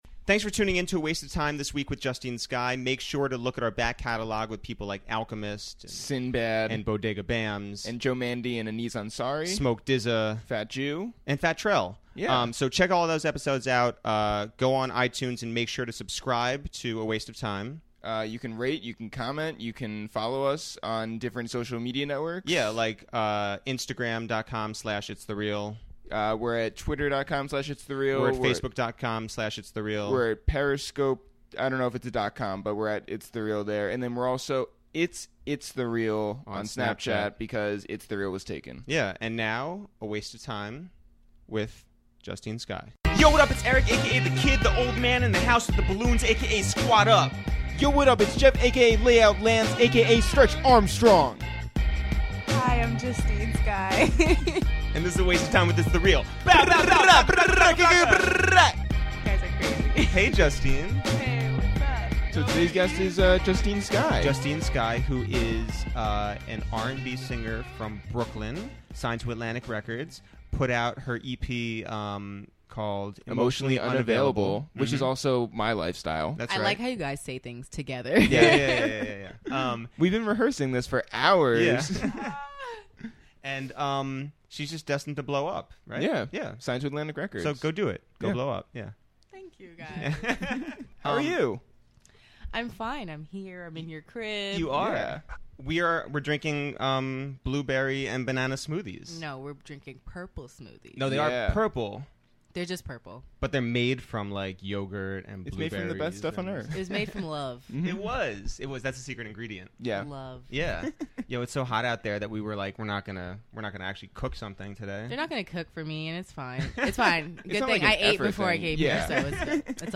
R&B starlet Justine Skye literally rolled into our apartment for this week's episode of A Waste of Time with ItsTheReal, where we sipped on purple smoothies and talked about Justine's homeschooling, Jaden Smith doing the Nae Nae, and Coachella jail. We discussed who's been looking for DJ Mustard, which Instagram pictures Katy Perry likes, and singing karaoke with Adrienne Bailon.